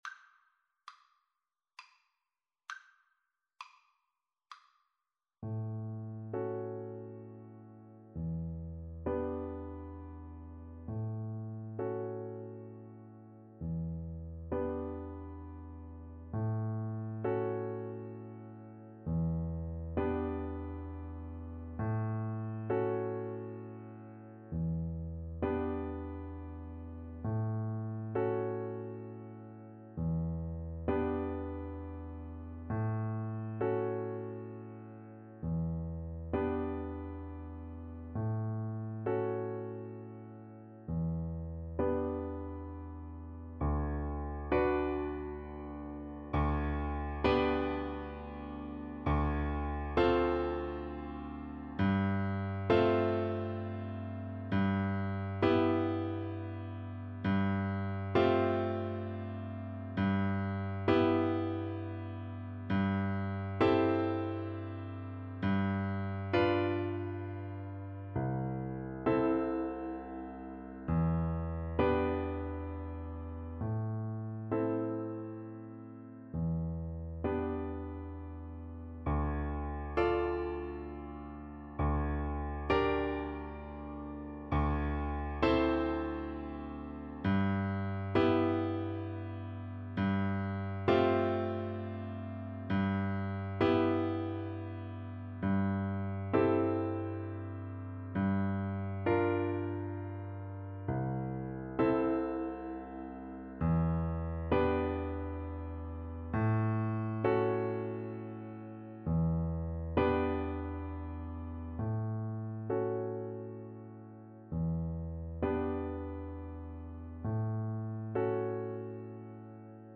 Lent et triste = 66
Classical (View more Classical Piano Duet Music)